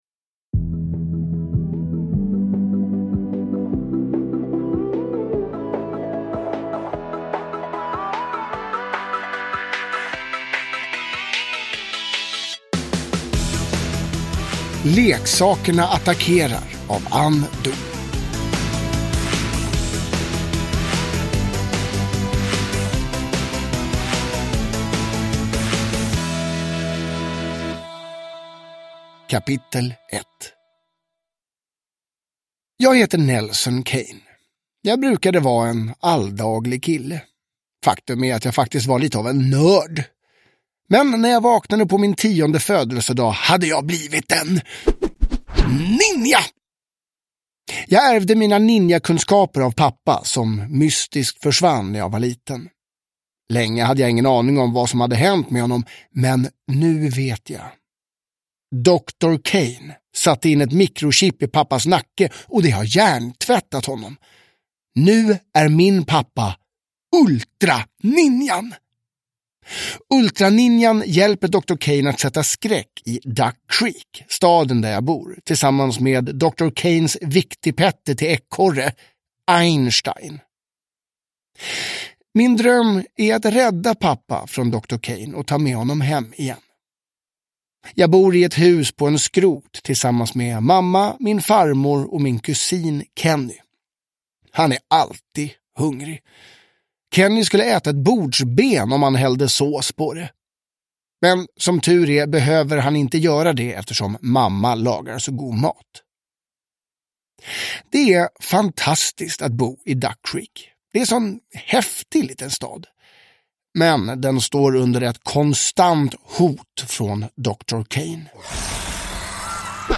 Leksakerna attackerar – Ljudbok